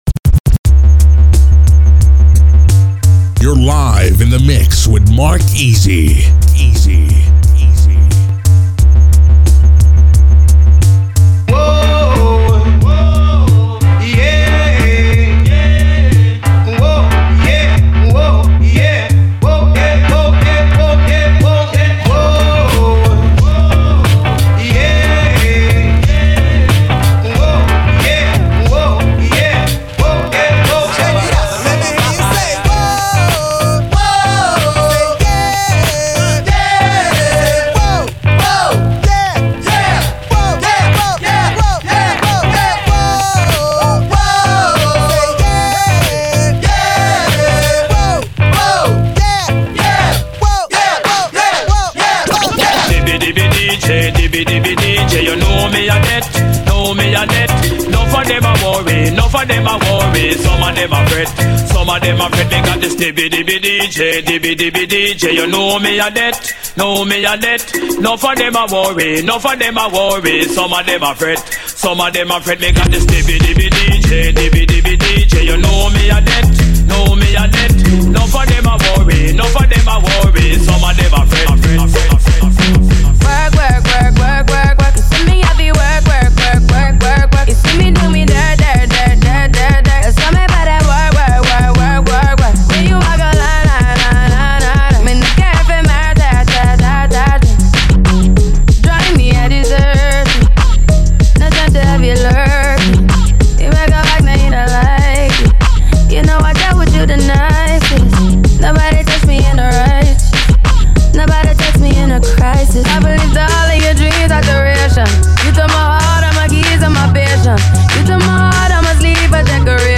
exclusive mix